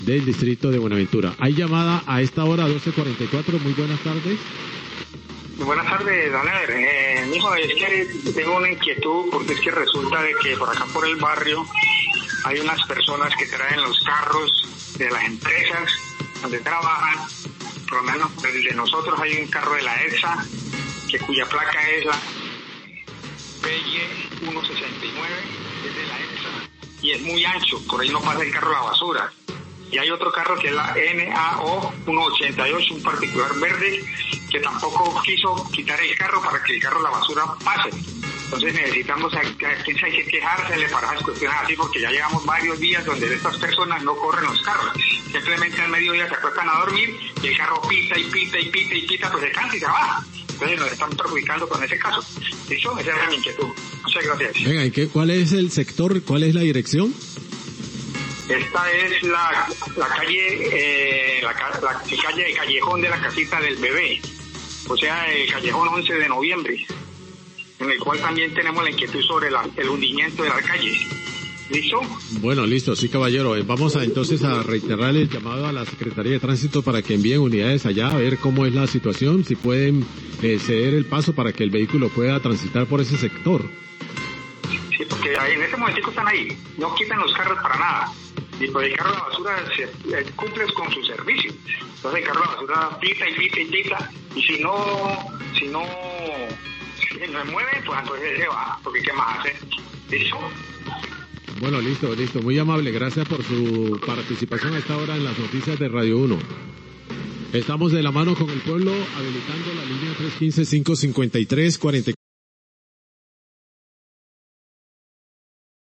Oyente se queja por vehículo de la empresa EPSA que obstaculiza la vía,1247pm